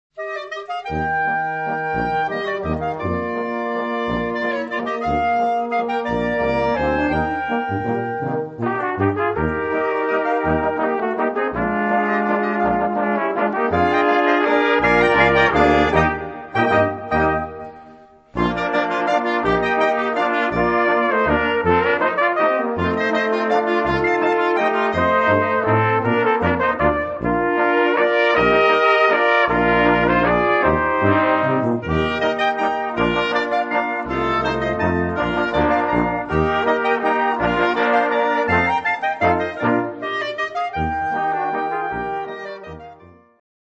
Gattung: für gemischtes Ensemble
Besetzung: Ensemble gemischt